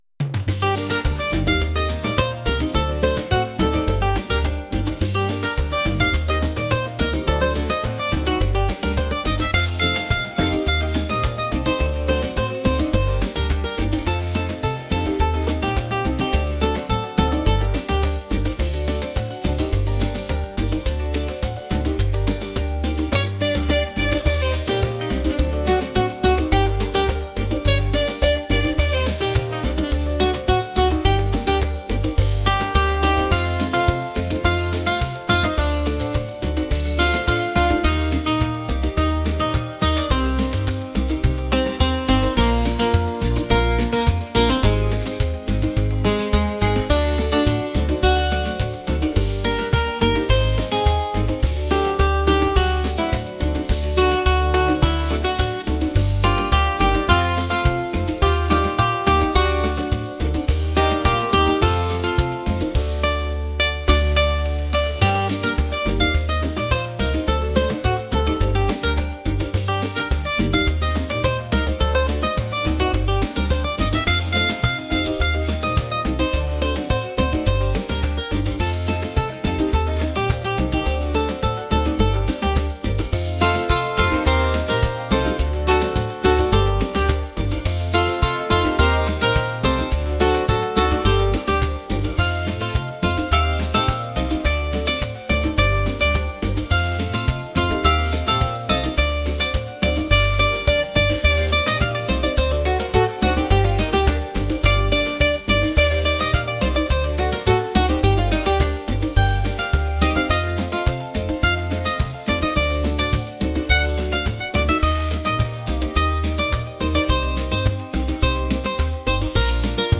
upbeat | energetic | latin